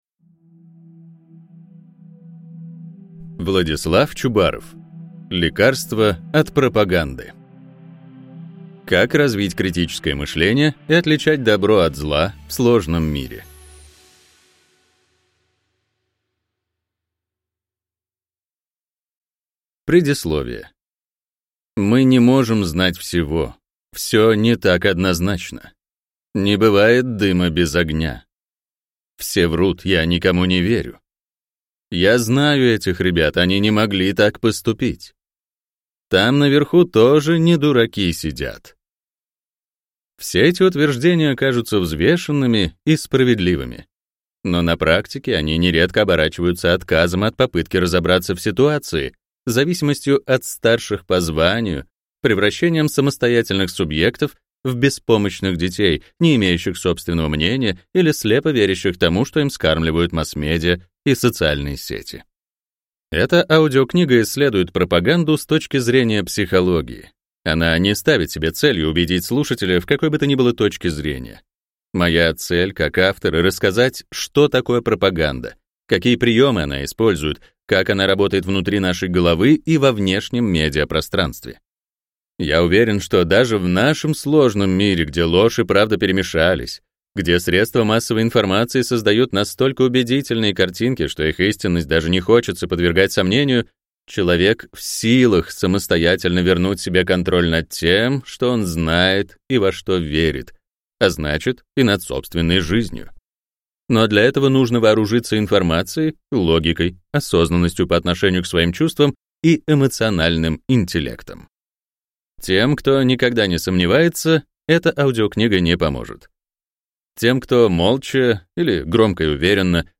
Аудиокнига Лекарство от пропаганды | Библиотека аудиокниг